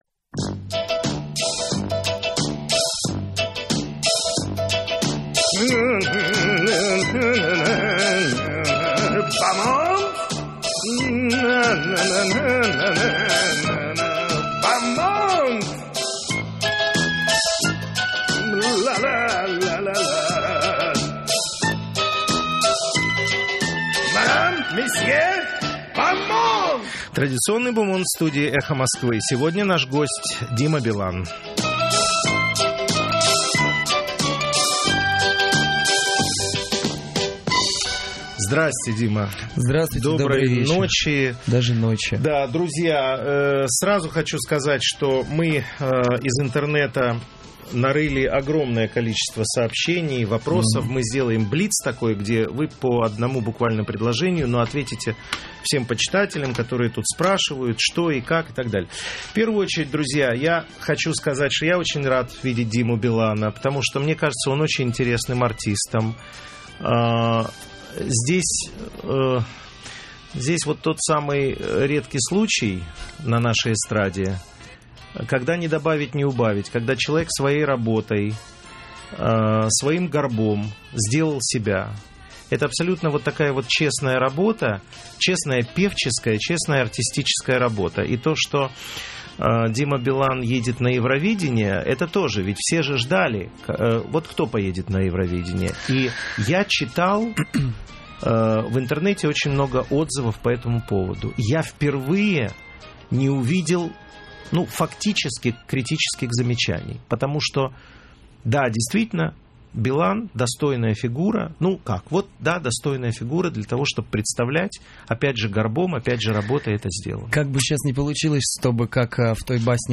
В эфире радиостанции «Эхо Москвы» - Дима Билан, певец, представитель России на "Евровидении" 2006.